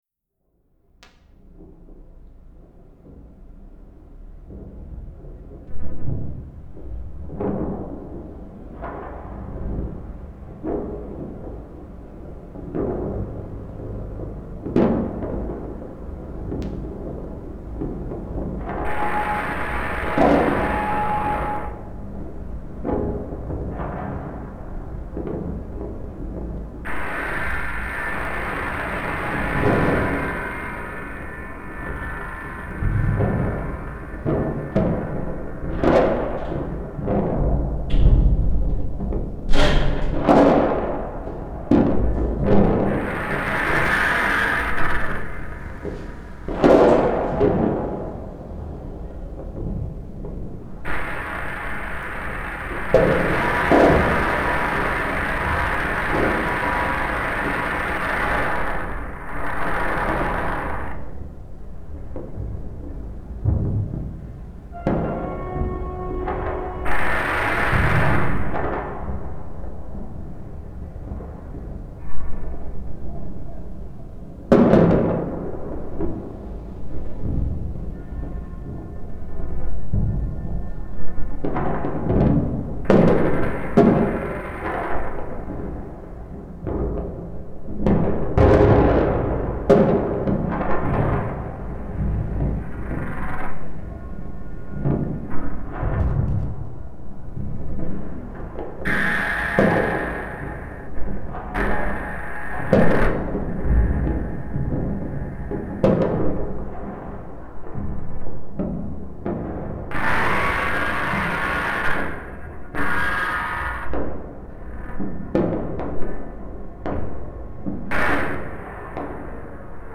Sound field recordings